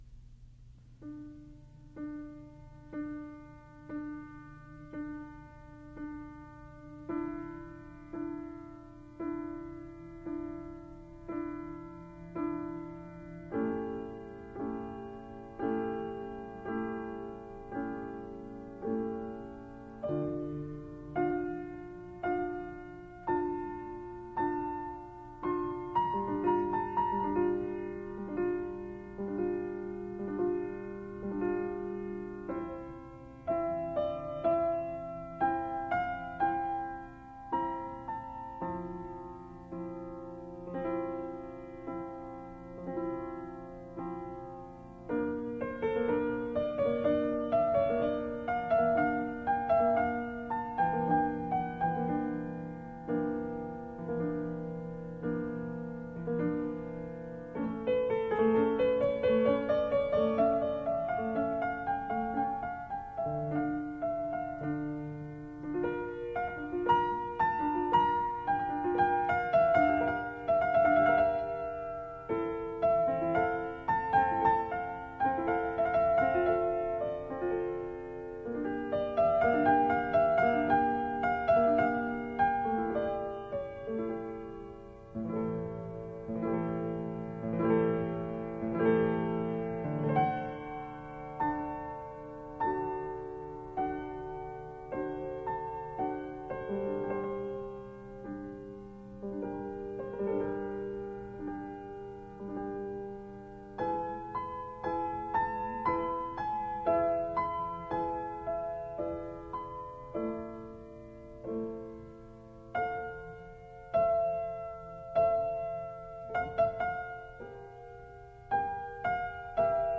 这套全集每张CD都用原版LP转录，并以LP的封面作为CD封面，非常具有收藏价值。